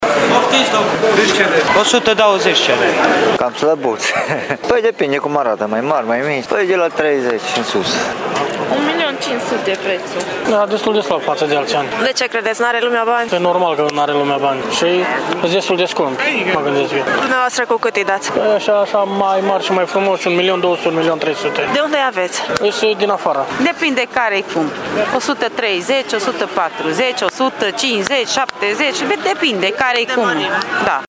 Vânzătorii spun că lumea nu se prea înghesuie la brazi și că așteaptă, ca de obicei, ultimele zile.